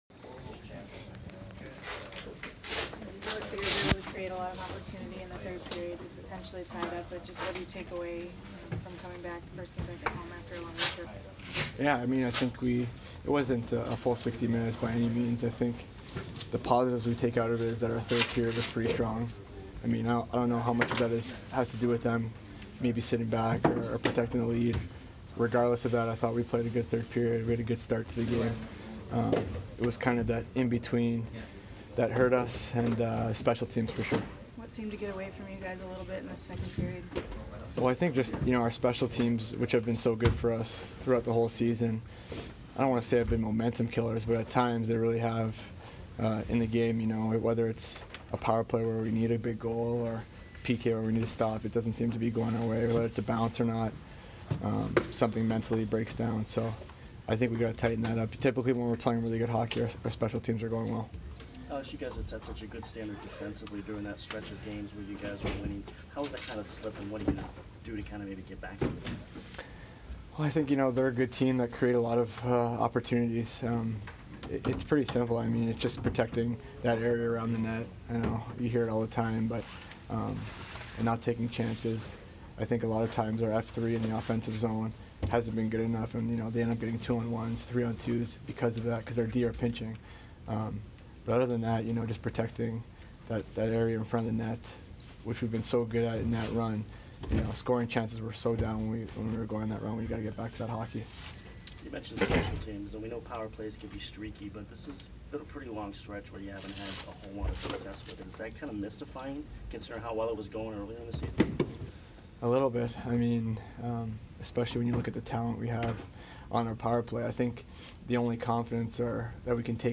Alex Killorn post-game 2/25